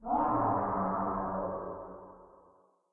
Commotion3.ogg